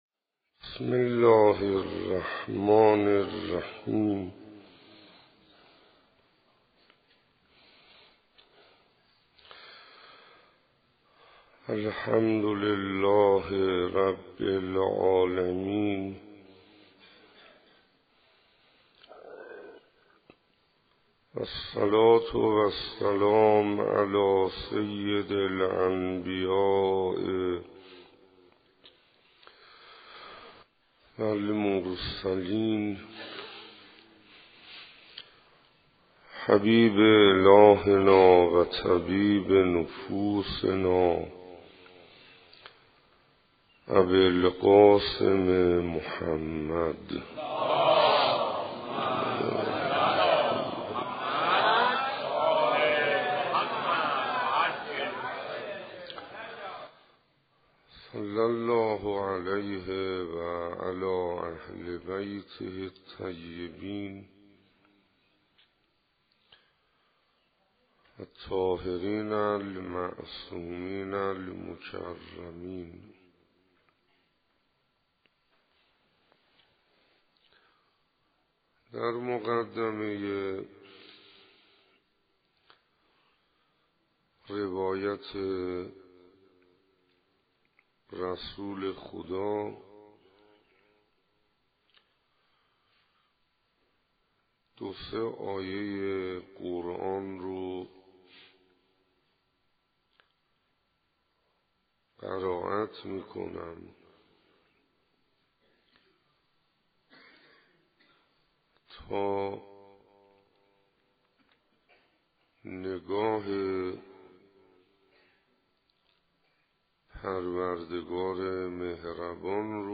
حسینیهٔ هدایت دههٔ سوم محرم 95 سخنرانی هفتم_نشانه های محبت اللهی به بندگان